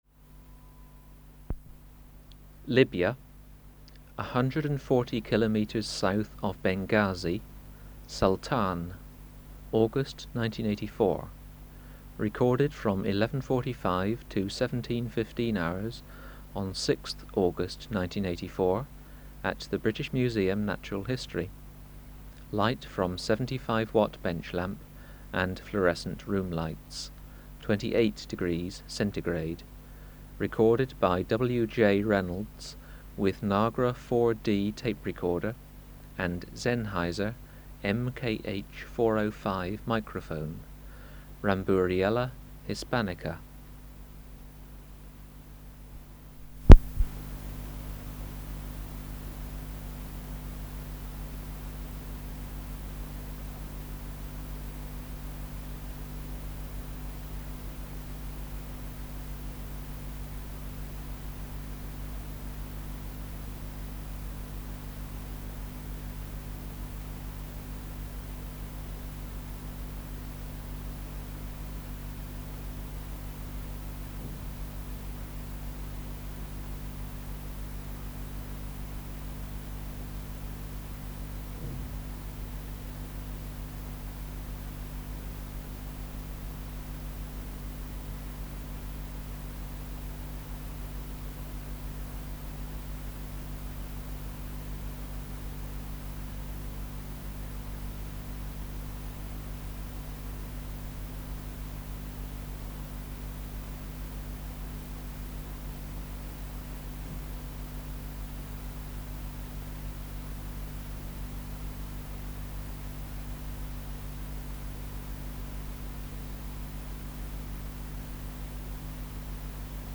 Species: Ramburiella (Ramburiella) hispanica
Recording Location: BMNH Acoustic Laboratory
Reference Signal: 1 kHz for 10 s
Substrate/Cage: Small recording cage
Microphone & Power Supply: Sennheiser MKH 405 Filter: Low Pass, 24 dB per octave, corner frequency 20 kHz